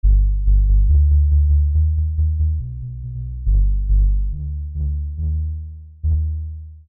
在睡梦中放屁的人
标签： 打鼾 震耳欲聋 之后 放屁 睡觉 起来 恶心
声道立体声